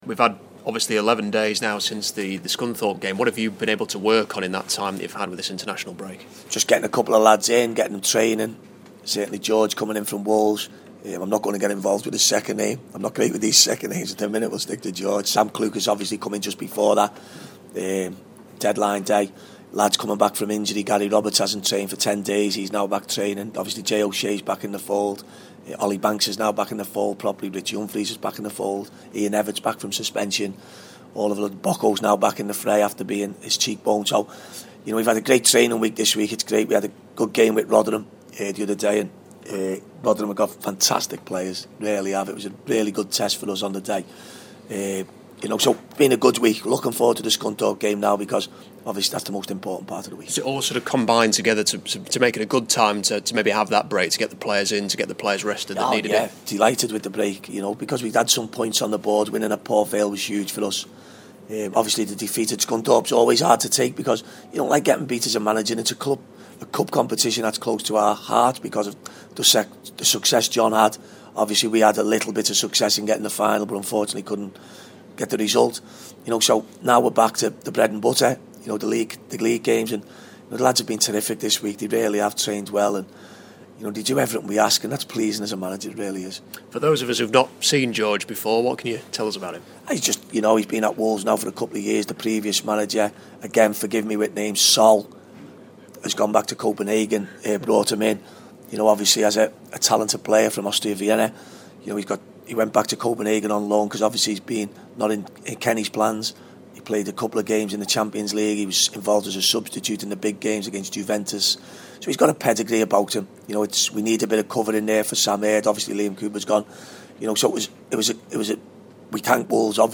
INTERVIEW: Chesterfield boss Paul Cook speaking ahead of the Scunthorpe game